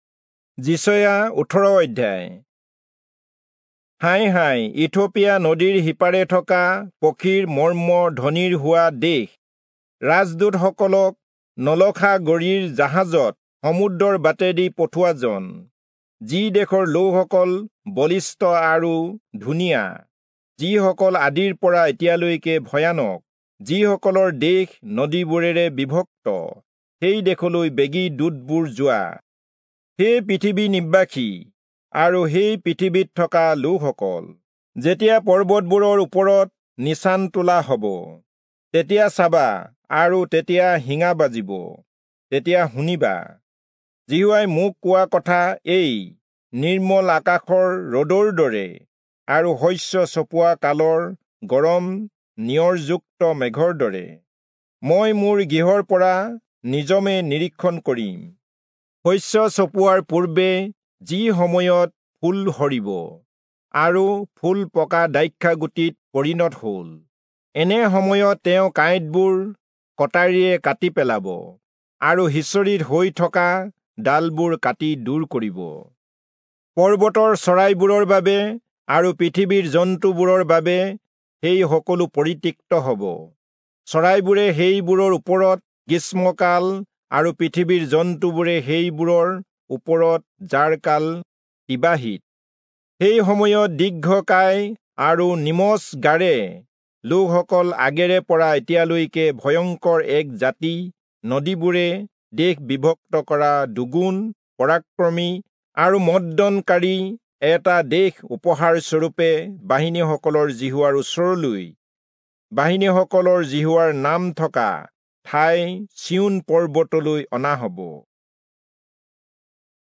Assamese Audio Bible - Isaiah 59 in Hcsb bible version